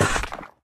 Minecraft Version Minecraft Version 1.21.4 Latest Release | Latest Snapshot 1.21.4 / assets / minecraft / sounds / ui / stonecutter / cut1.ogg Compare With Compare With Latest Release | Latest Snapshot